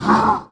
demage_1.wav